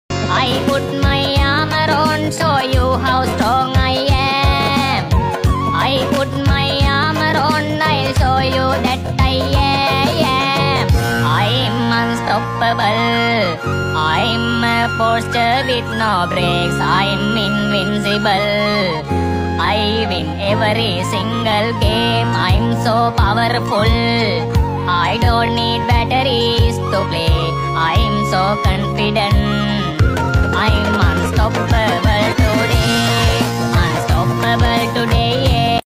I am unstoppable. funny song. sound effects free download